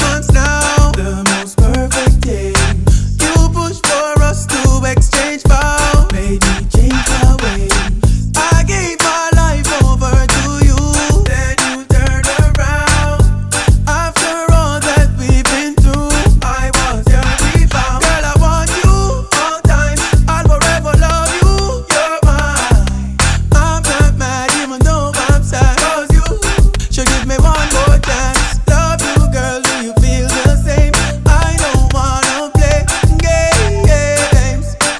Жанр: Реггетон